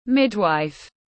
Hộ sinh tiếng anh gọi là midwife, phiên âm tiếng anh đọc là /ˈmɪd.waɪf/.
Midwife /ˈmɪd.waɪf/